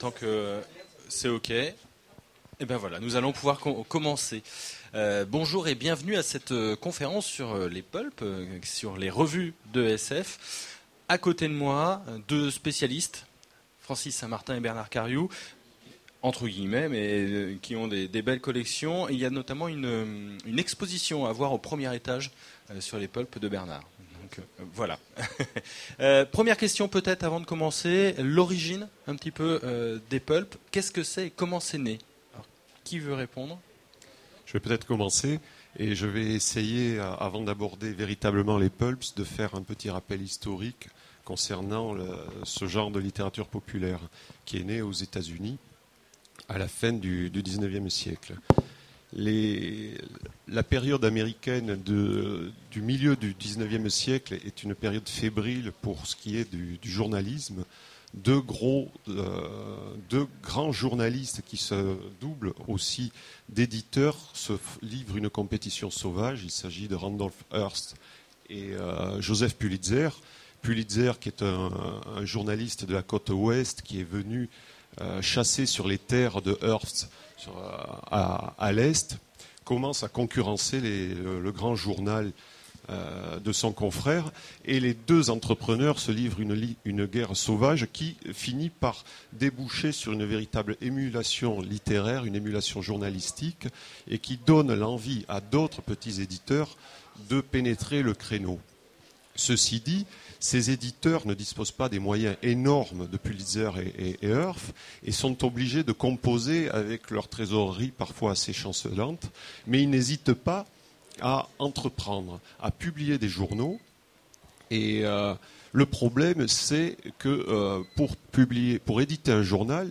Voici l'enregistrement de la conférence Des pulps aux revues modernes à la convention 2010